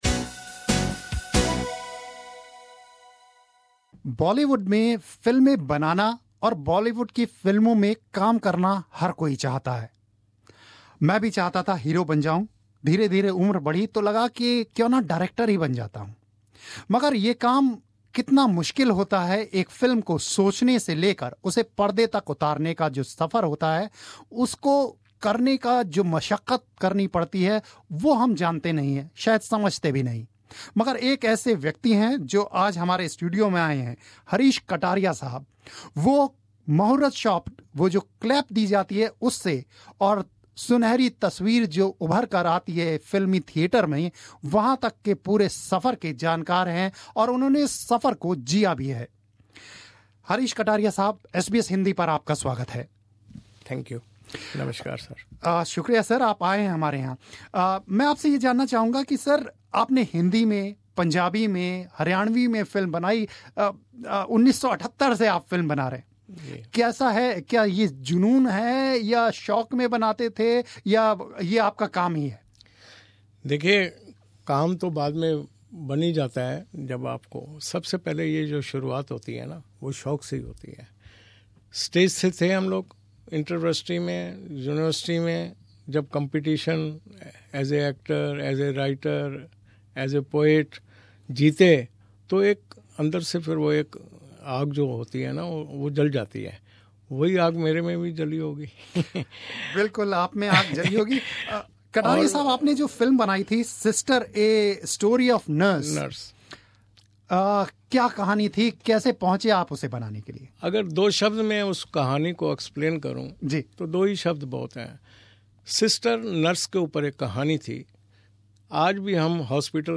had a candid conversation with him about the state of regional film industry in India and Australia.